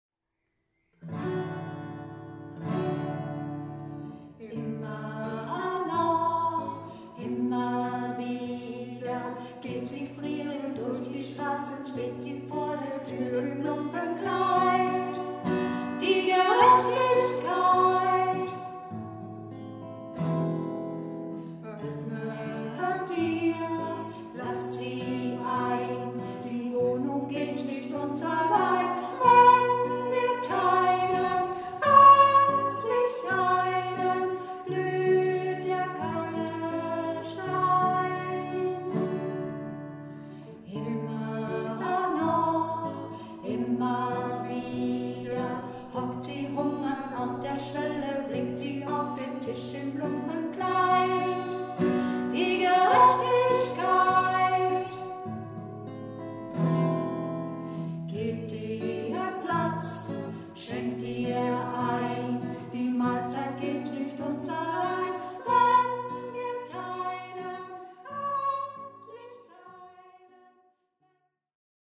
Besetzung: Alt, Gitarre